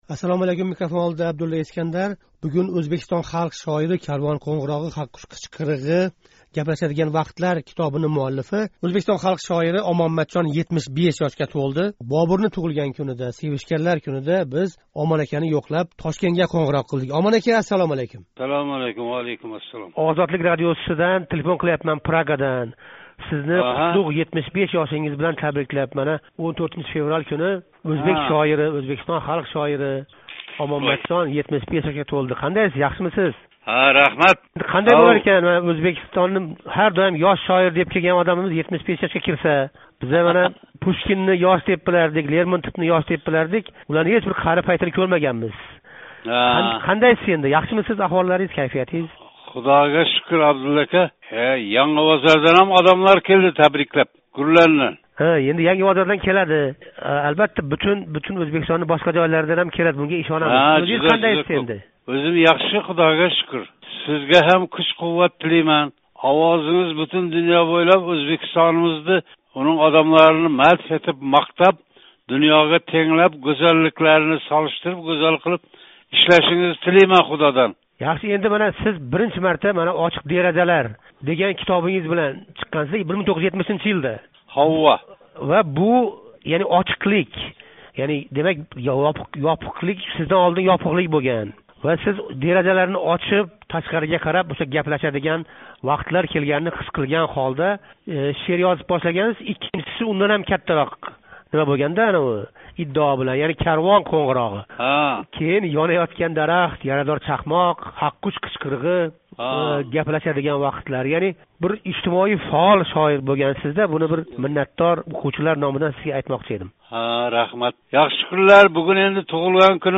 O‘zbekiston xalq shoiri Omon Matjon 14 fevral kuni 75 yoshga to‘ldi. Ozodlik bilan qisqa suhbatda shoir o‘z o‘quvchilari va Ozodlik tinglovchilariga shoirni unutmaganlari uchun minnatdorlik bildirdi.